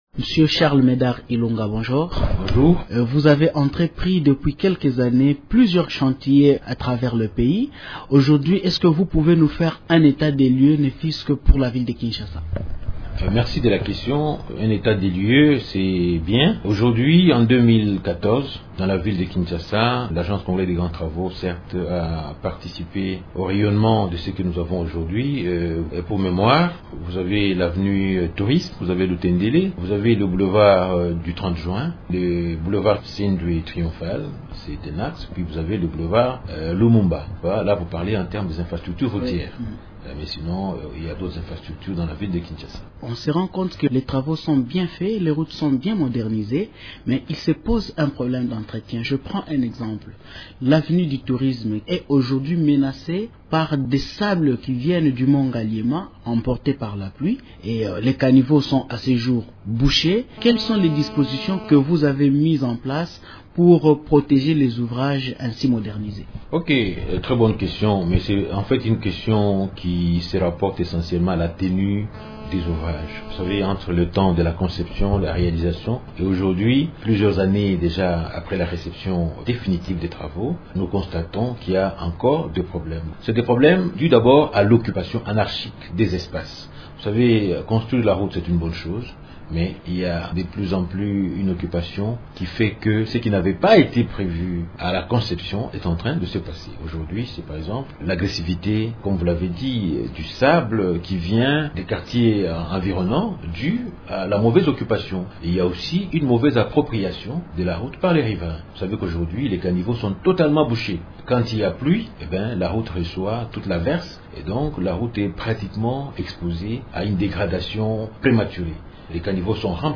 Le directeur général de l’Agence congolaise de grands travaux (ACGT), Charles Médard Ilunga, parle de la réhabilitation et de l’entretien des routes à Kinshasa.